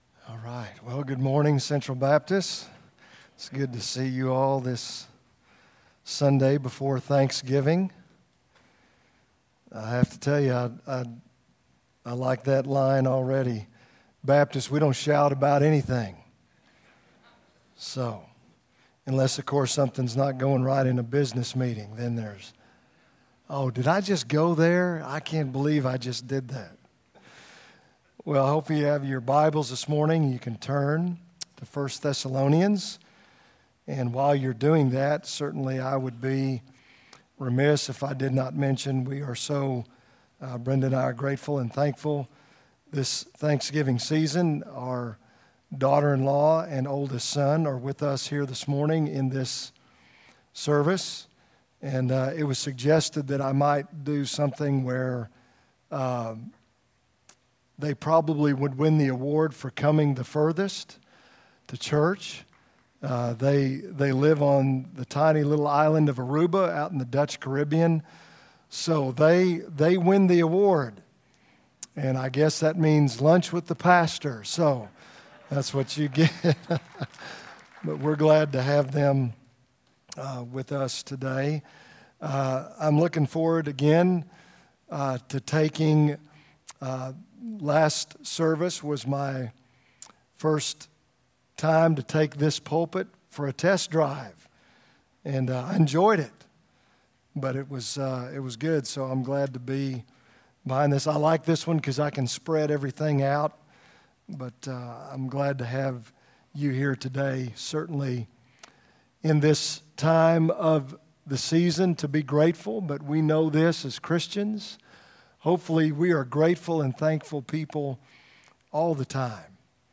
From Series: "2018 Sermons"